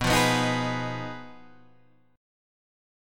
B 6th Flat 5th